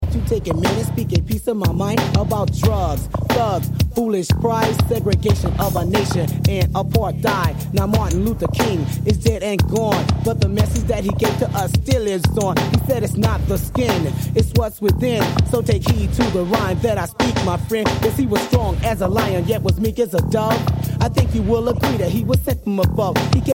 Mega rare early 90’s gangsta/conscious rap tape only single
Contains vocal and instrumental version.